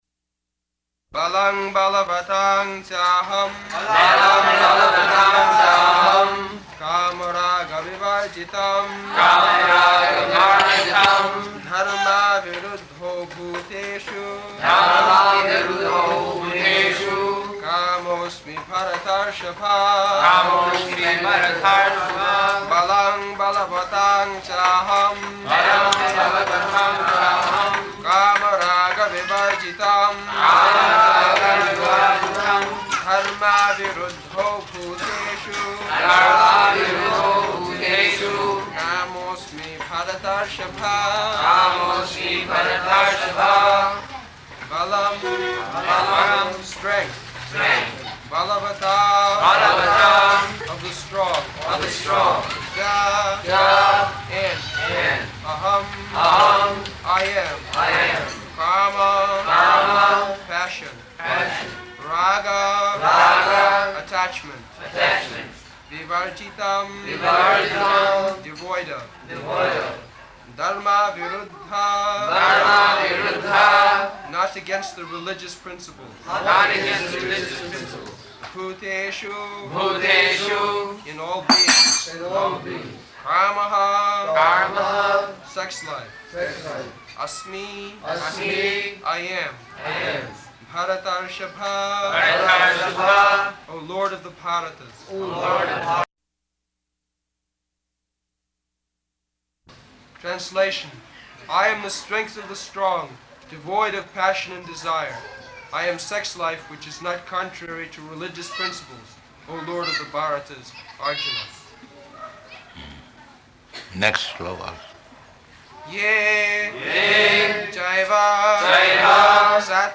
Location: Bombay
[leads chanting of verse, etc.]
[loud sounds of firecrackers in background] The one process is, as recommended by Caitanya Mahāprabhu, tṛṇād api sunīcena taror api sahiṣṇunā amāninā mānadena kīrtanīyaḥ sadā hariḥ [ Cc.